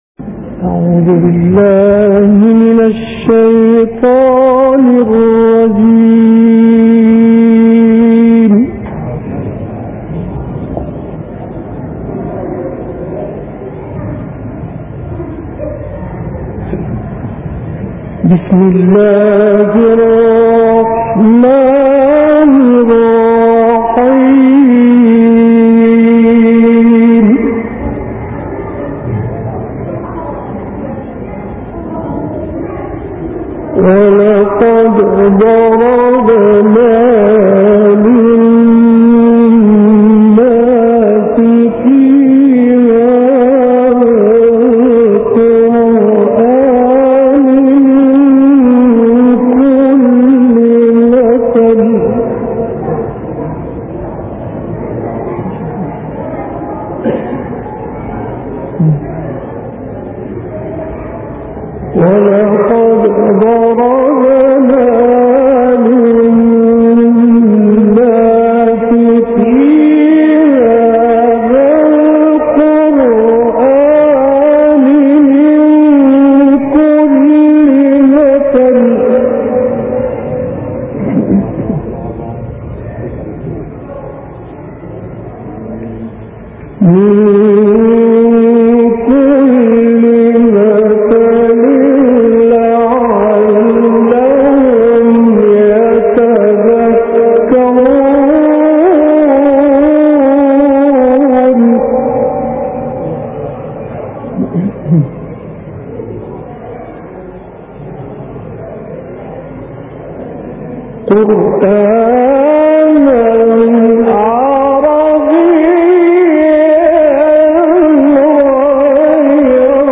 تلاوت مجلسی فرج الله شاذلی + دانلود
گروه فعالیت‌های قرآنی: تلاوت ماندگار از سوره زمر با صوت فرج الله شاذلی را می‌شنوید.